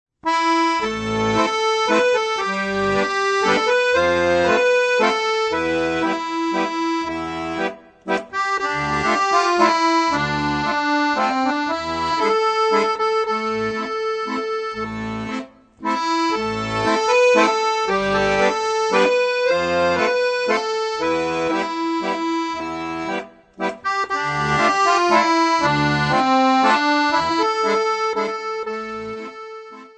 Besetzung: Akkordeon